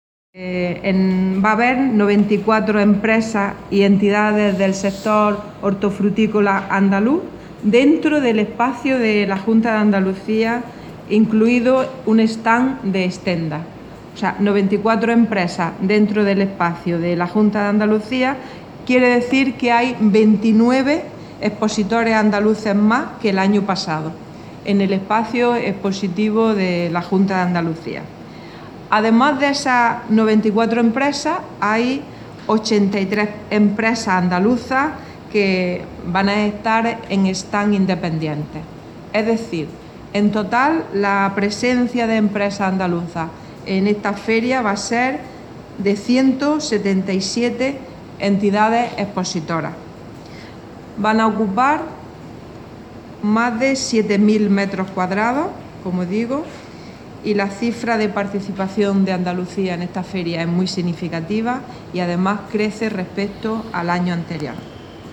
Declaraciones Carmen Ortiz sobre Fruit Attraction 2016